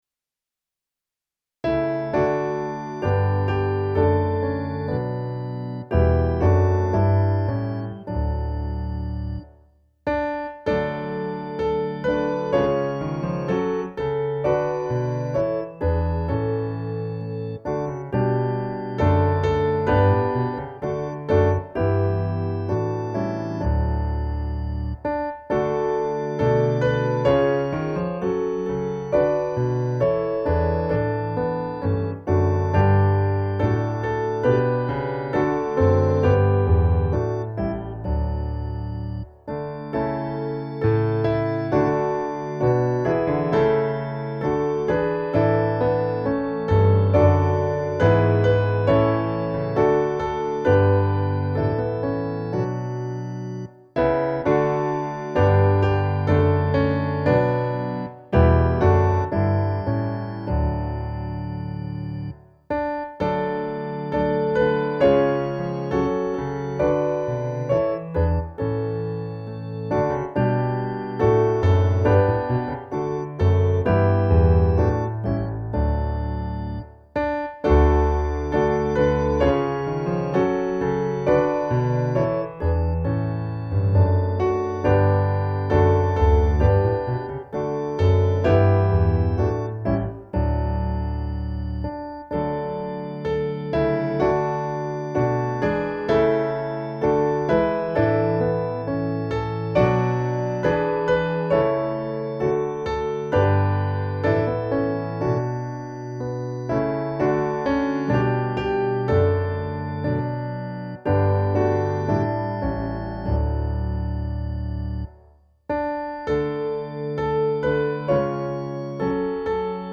Vad ljus över griften - musikbakgrund
Gemensam sång
Musikbakgrund Psalm